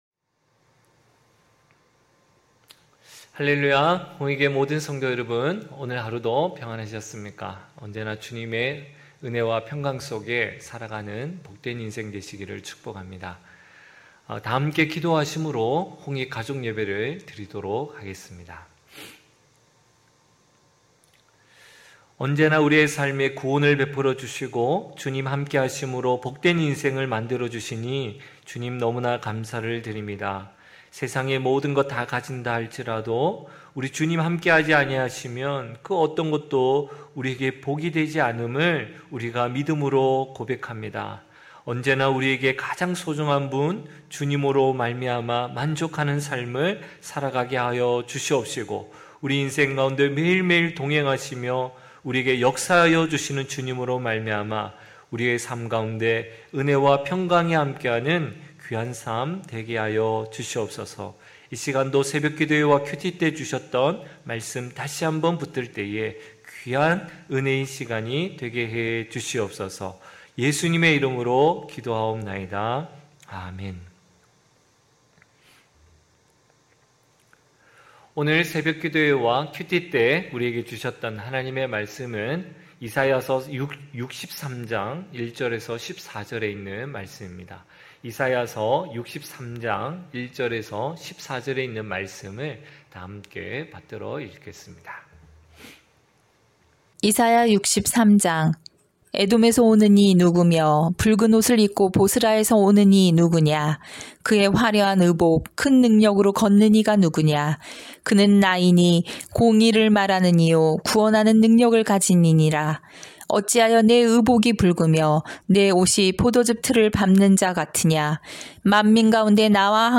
9시홍익가족예배(8월12일).mp3